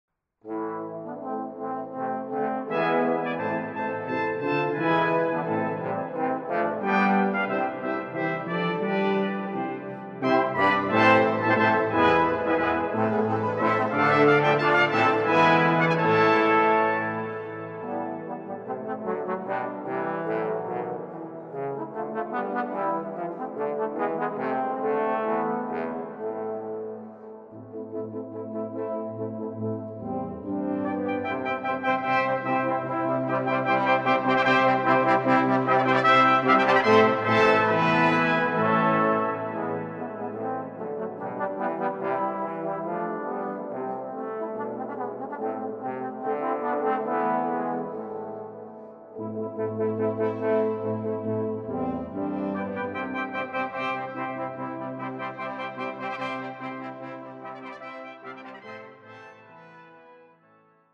4 Trumpets, 4 Trombones, Tuba Schwierigkeit